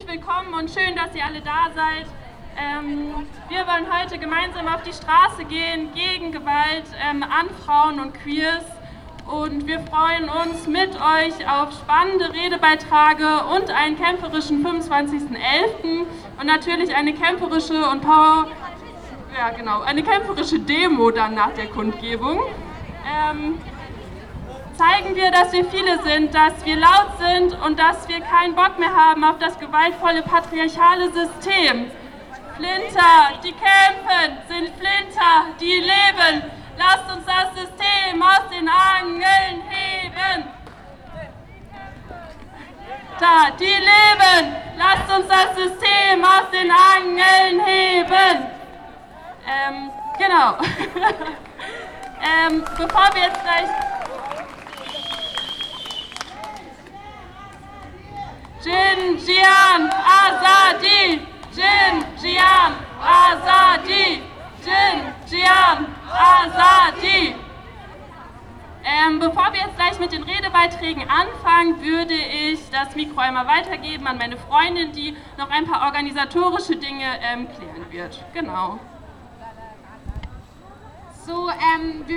Kämpferische Demo zum internationalen Tag gegen Gewalt an Frauen und Queers: "Wir wollen laut werden, gegen jegliche Form von Gewalt gegen FLINTA*"